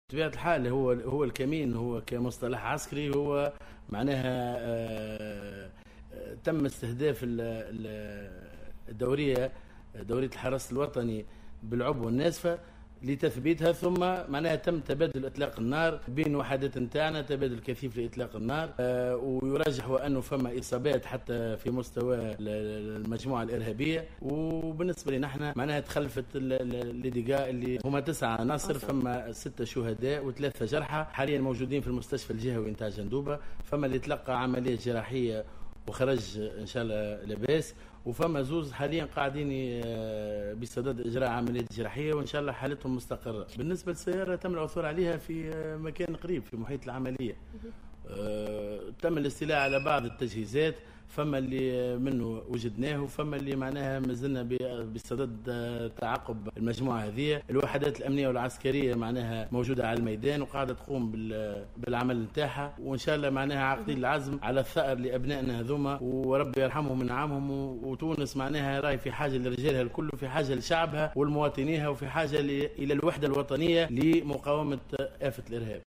أكد العميد سفيان الزعق الناطق الرسمي بإسم وزارة الداخلية في تصريح للوطنية الأولى مساء اليوم الأحد أنه تم الاستيلاء على بعض تجهيزات الأمنيين الذين استشهدوا في جندوبة مضيفا أن الوحدات الأمنية وجدت بعض التجهيزات والبحث جار عن البقية حسب قوله.